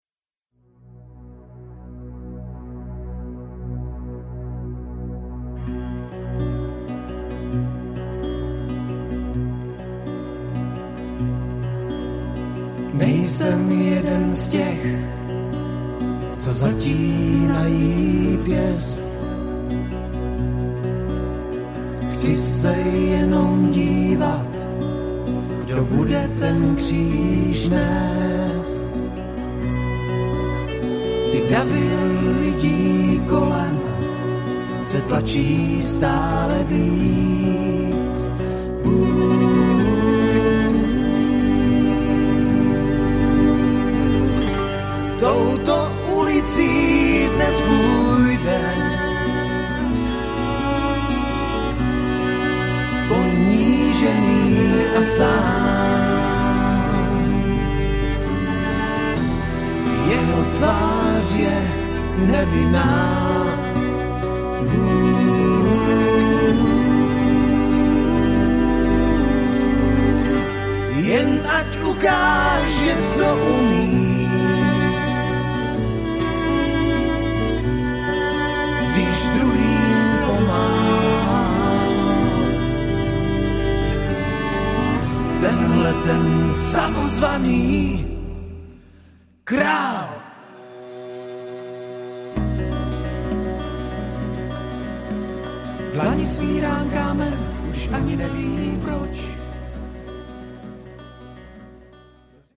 Žánr: Rock.